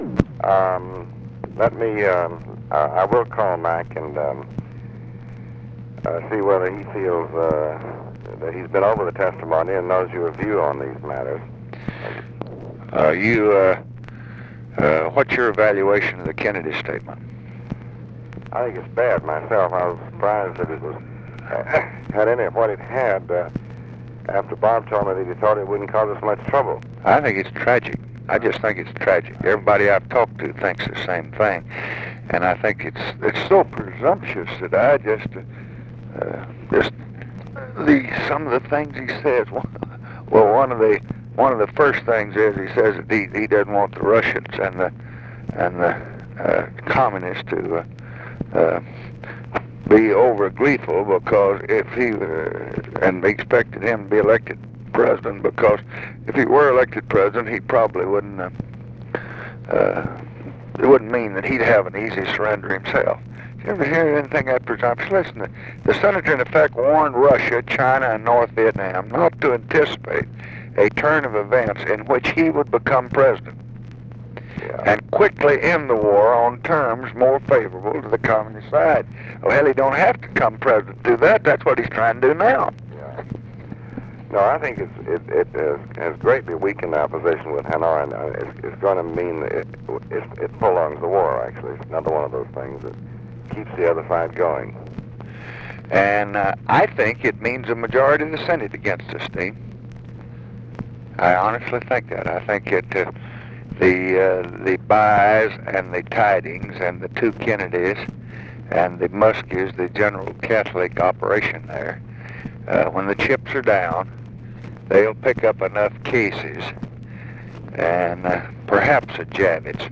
Conversation with DEAN RUSK, February 20, 1966
Secret White House Tapes